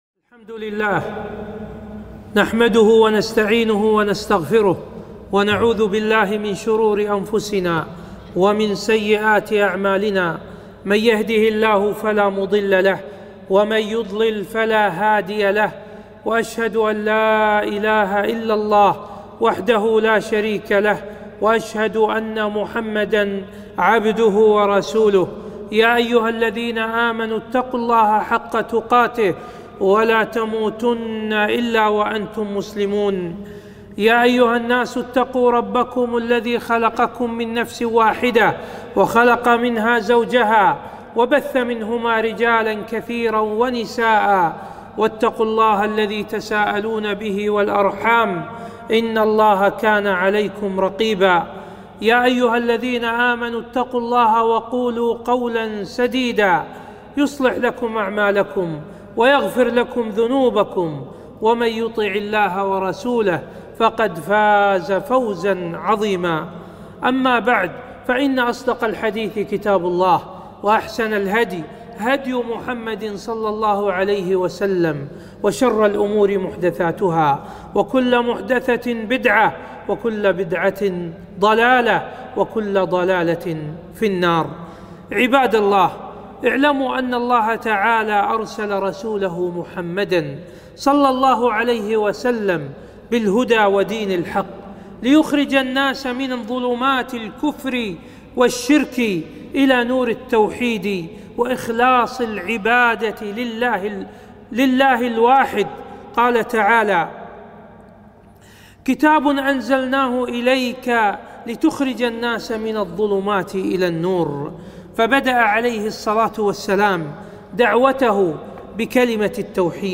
خطبة - وجوب التوحيد وفضله